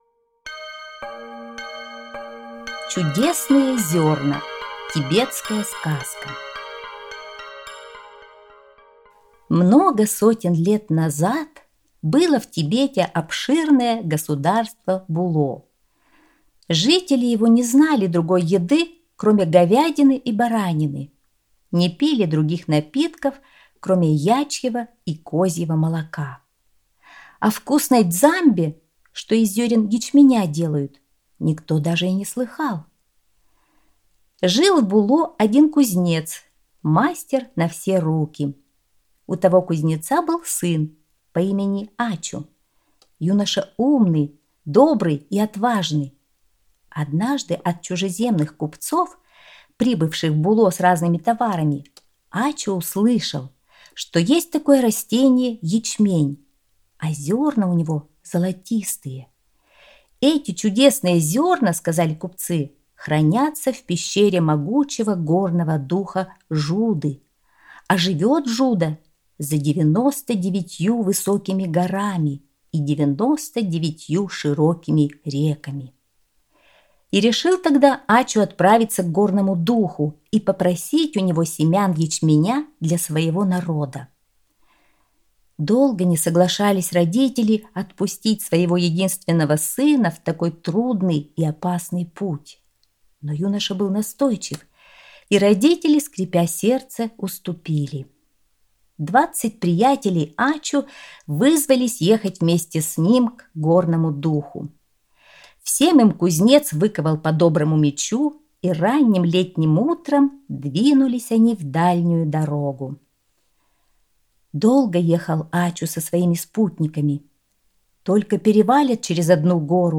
Чудесные зёрна - тибетская аудиосказка - слушать онлайн